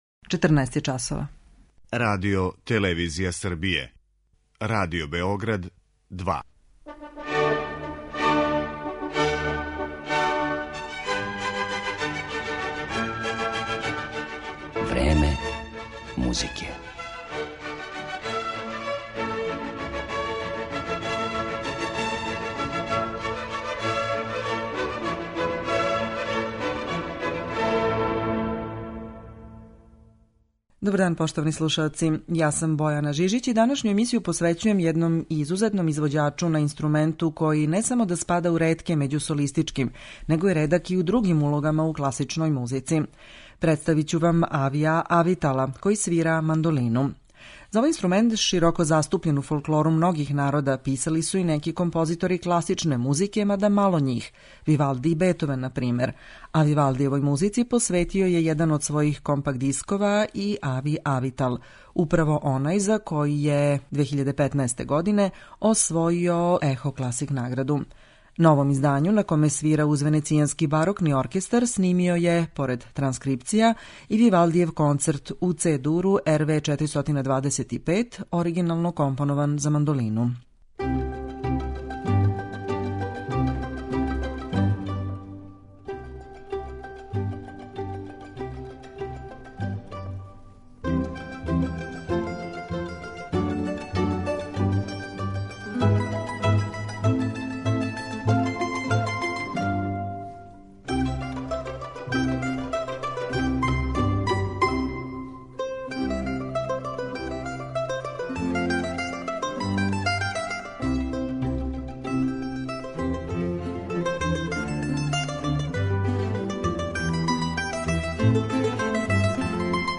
Данашња емисија је посвећена изузетном извођачу на инструменту који се не среће често у класичној музици, на мандолини.